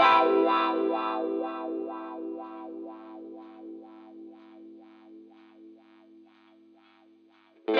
08 Wah Guitar PT3.wav